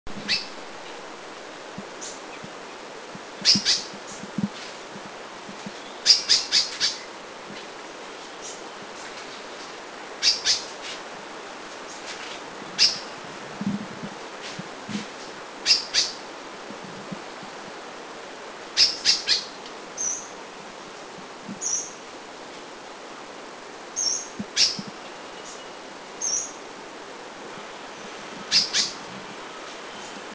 Streaked Spiderhunter
StreakedSpiderhunter.mp3